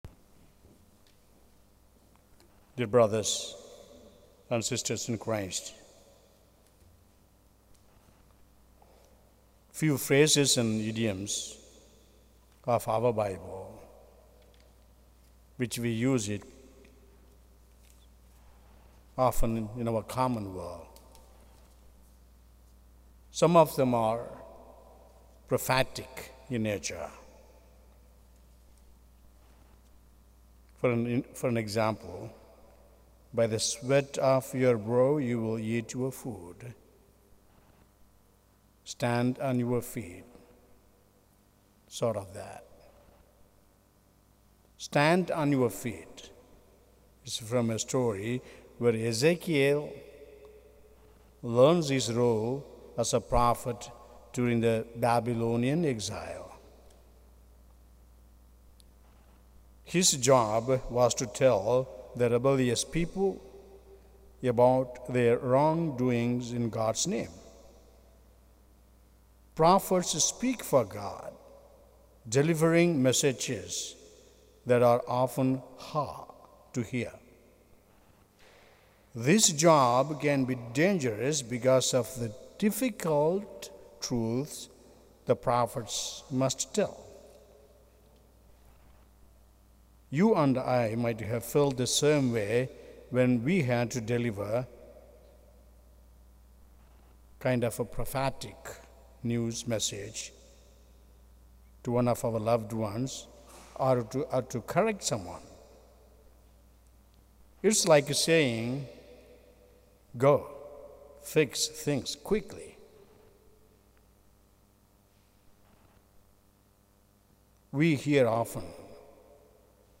Confirmation Mass